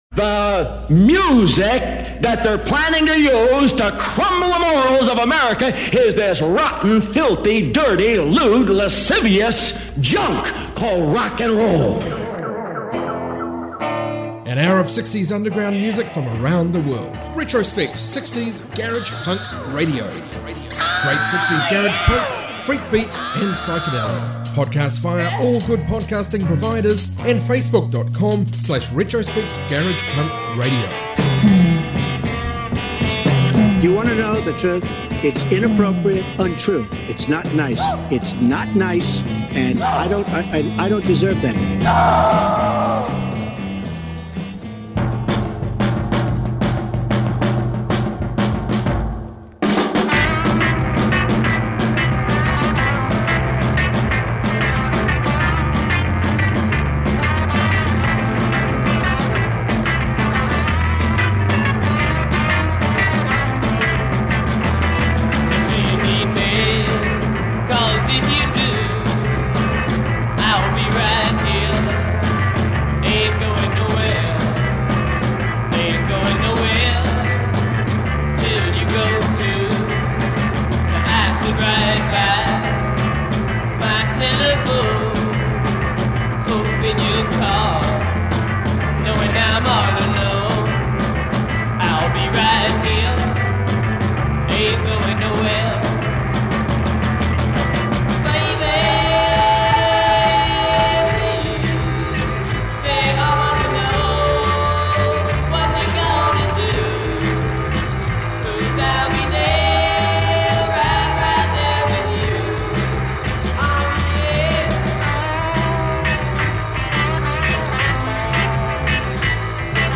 60s garage rock, garage punk, proto-punk, freakbeat and more